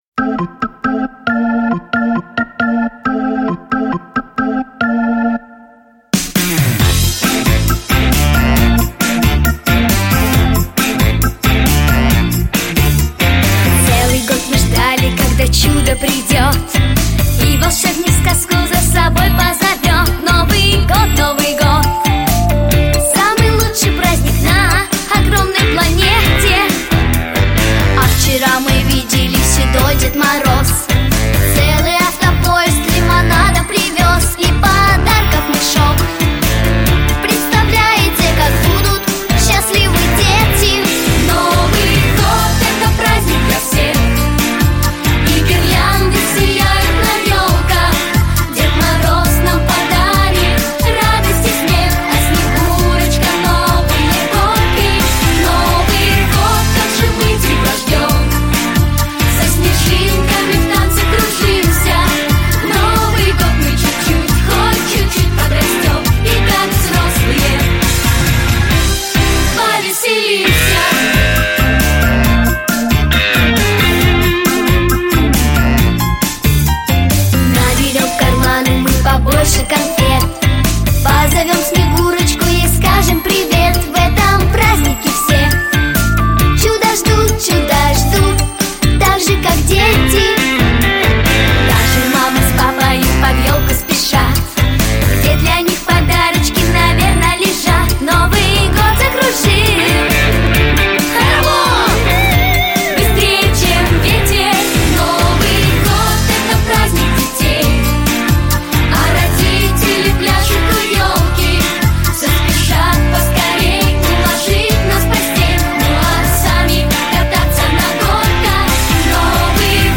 • Категория: Детские песни
Детский эстрадный ансамбль